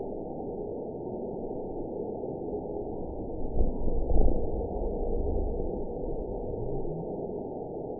event 919706 date 01/18/24 time 03:54:03 GMT (1 year, 3 months ago) score 9.29 location TSS-AB09 detected by nrw target species NRW annotations +NRW Spectrogram: Frequency (kHz) vs. Time (s) audio not available .wav